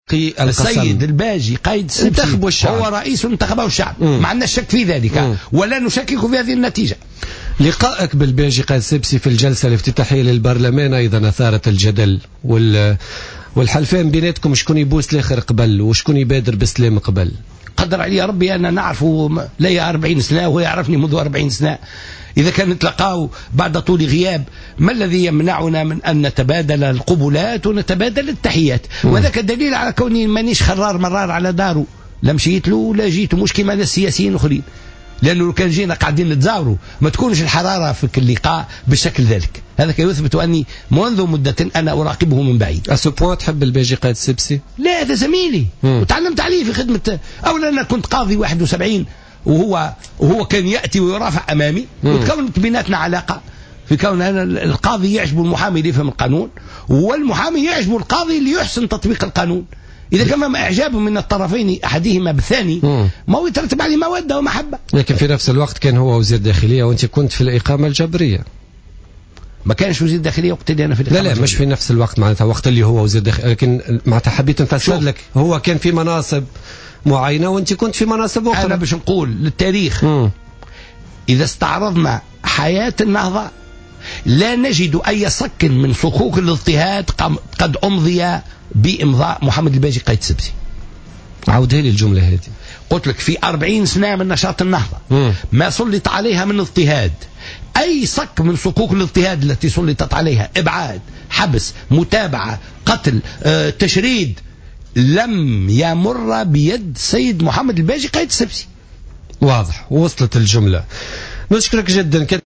Lors de son passage dans l’émission Politica du mercredi 24 décembre 2014, Abdelfatteh Mourou, leader au mouvement Ennahdha et vice président du parlement du peuple tunisien, est revenu sur les 40 années de militantisme de son mouvement.